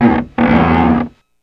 Index of /90_sSampleCDs/E-MU Producer Series Vol. 3 – Hollywood Sound Effects/Miscellaneous/Rubber Squeegees
SQUEEGEE 3.wav